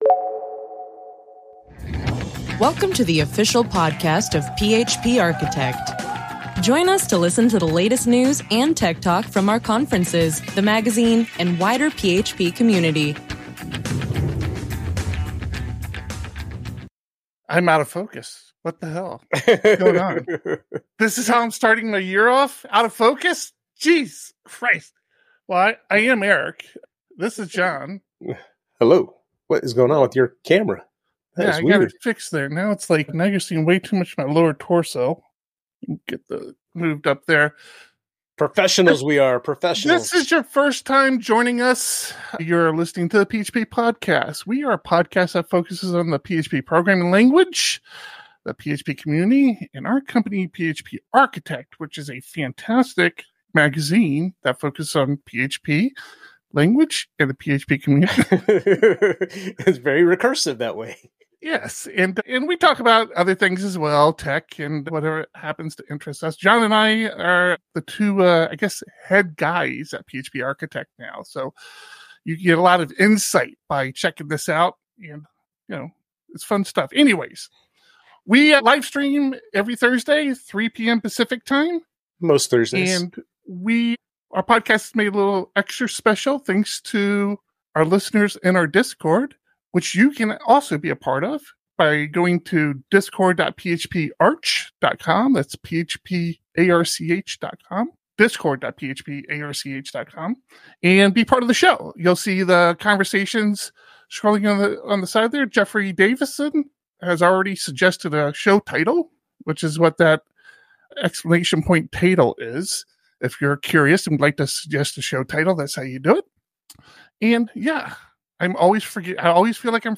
The PHP Podcast streams the recording of this podcast live, typically every Thursday at 3 PM PT.